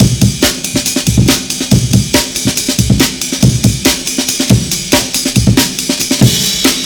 The tempo is 140 BPM.